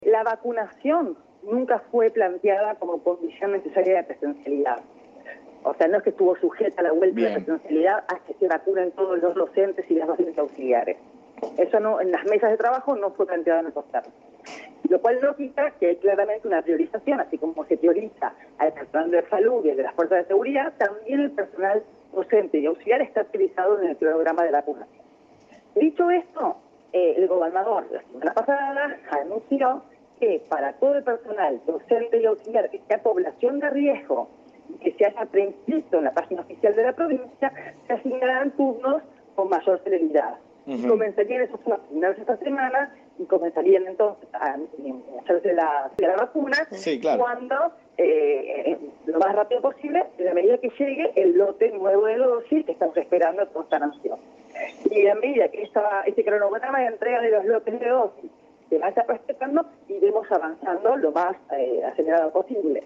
Declaraciones de Agustina Vila en Radio AM Provincia